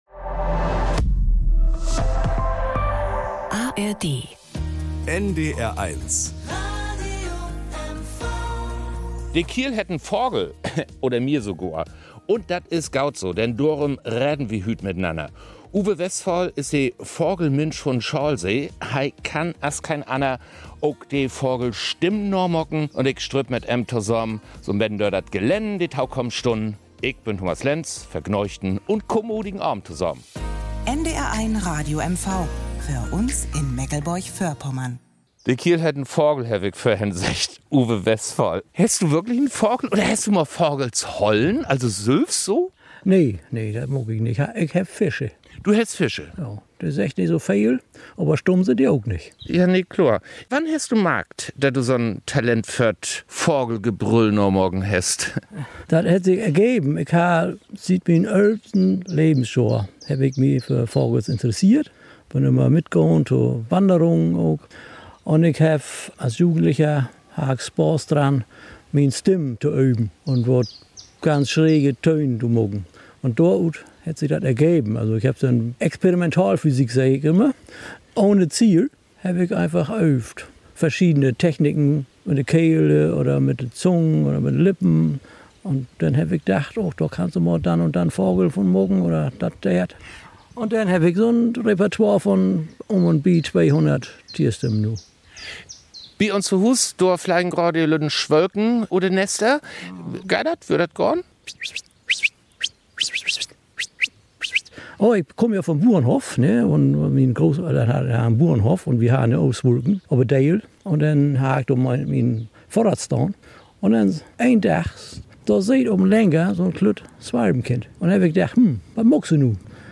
Eine besondere, an Tierstimmen reiche Klönkist.